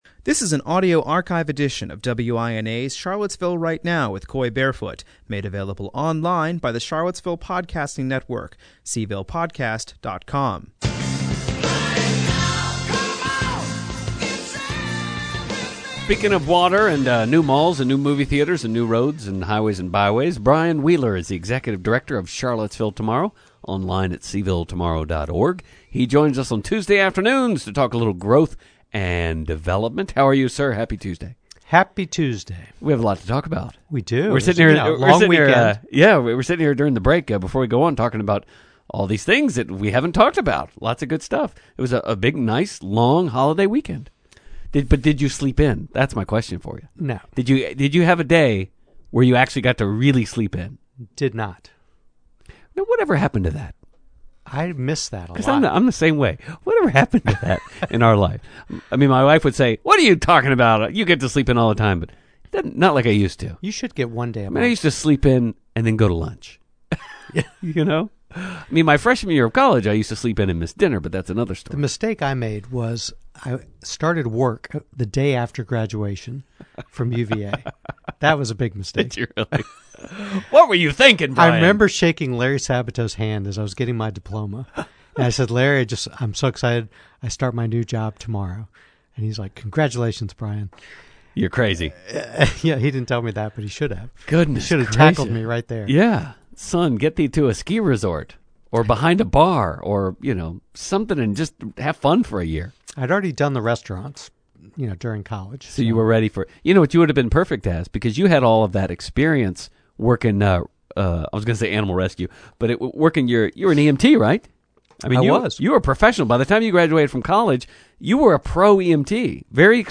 After a discussion of where to eat Williamsburg, a caller suggests that Charlottesville Tomorrow conduct a search of the best hole-in-the-walls to eat in Charlottesville.